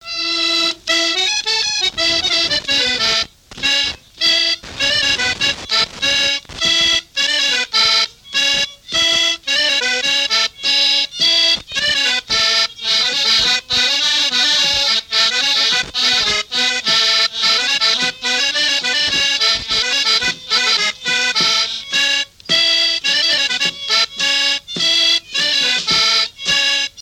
danse : polka piquée
Pièce musicale inédite